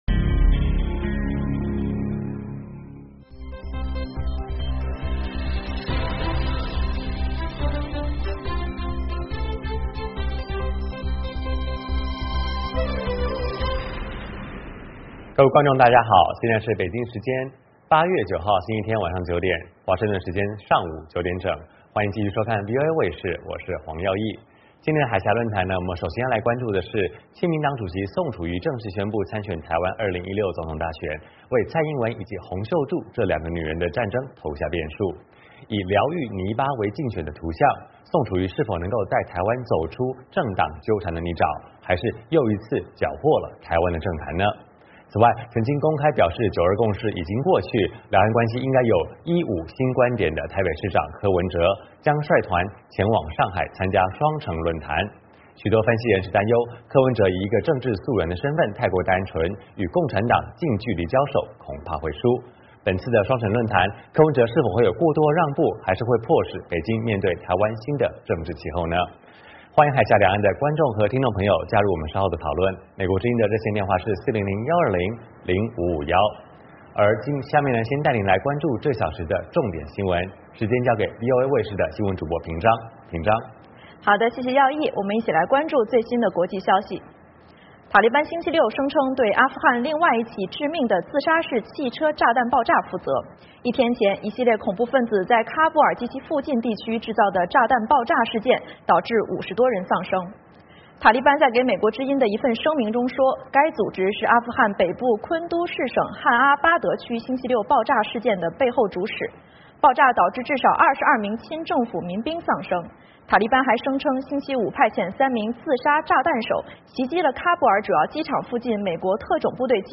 VOA卫视第二小时播出《海峡论谈》。节目邀请华盛顿和台北专家学者现场讨论政治、经济等各种两岸最新热门话题。